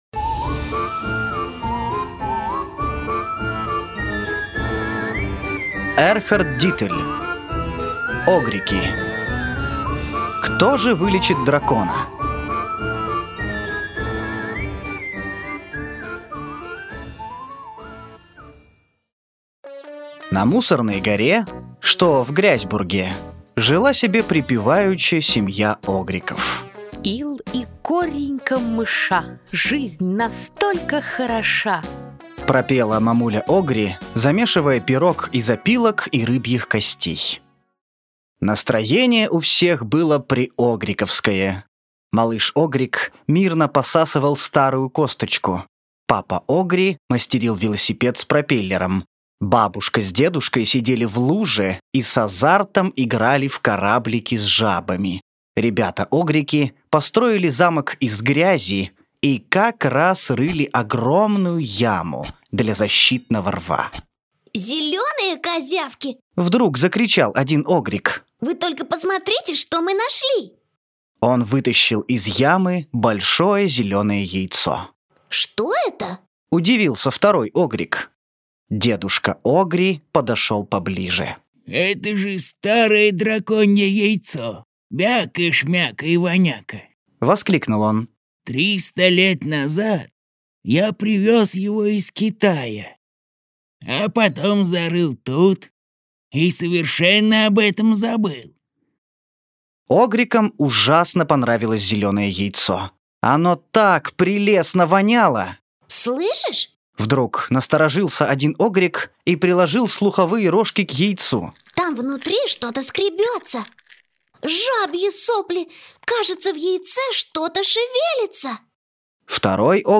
Аудиокнига Огрики: Кто же вылечит дракона? Сборник историй | Библиотека аудиокниг